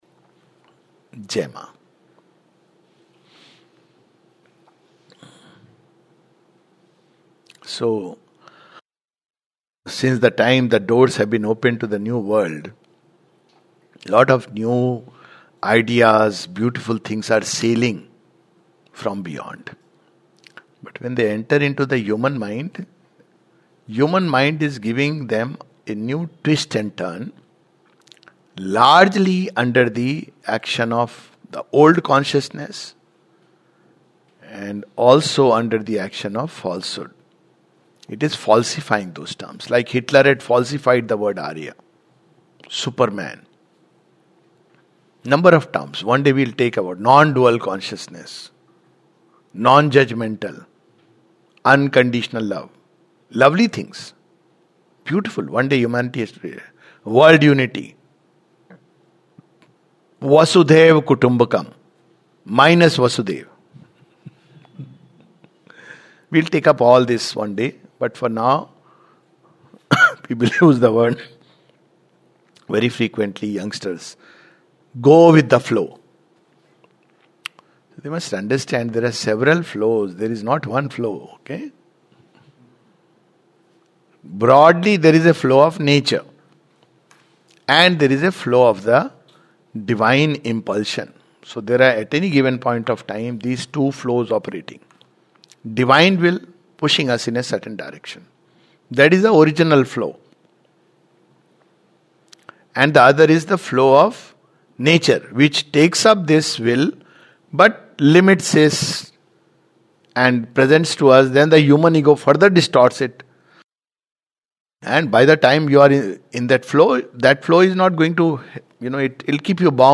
A brief reflection